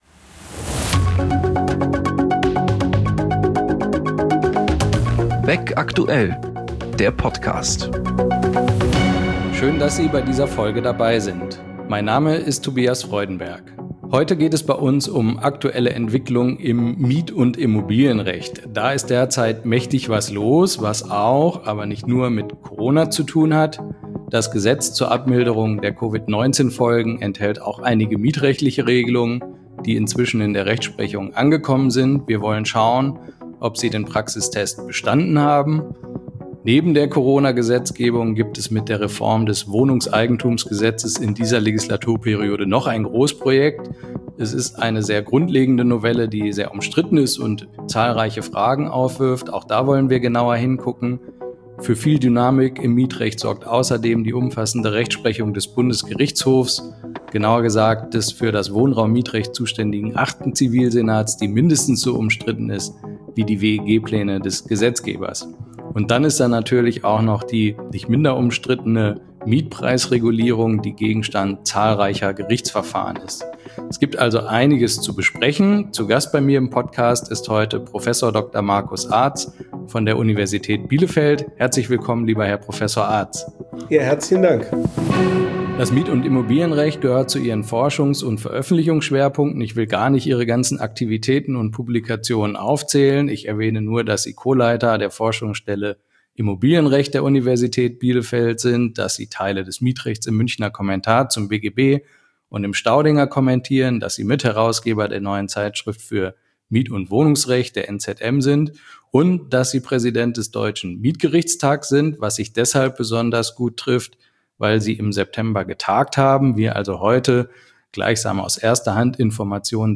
Interview-Podcast des führenden juristischen Fachverlags C.H.BECK zu aktuellen Entwicklungen, Hintergründen und Persönlichkeiten aus der Welt des Rechts.